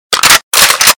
上膛2.mp3